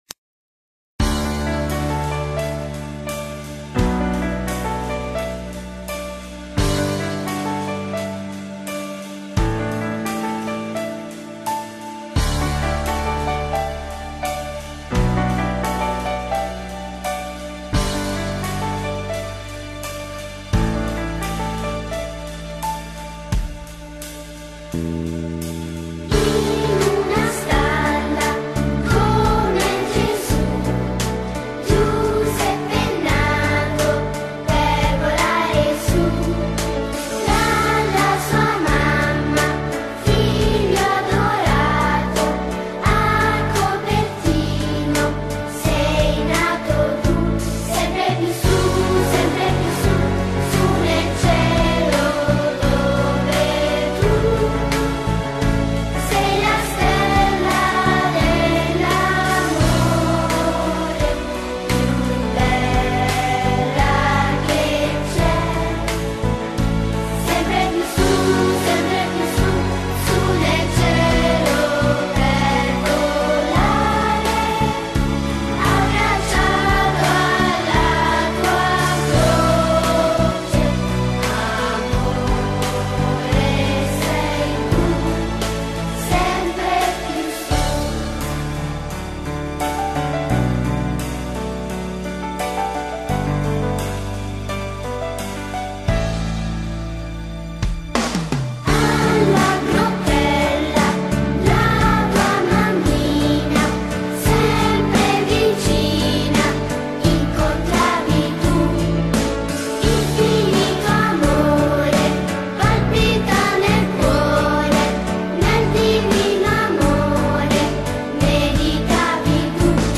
Gli alunni del nostro Istituto hanno accolto al parco della Grottella l’arrivo delle Sacre Spoglie di SAN GIUSEPPE da COPERTINO
Al suo passaggio lo hanno salutato con il canto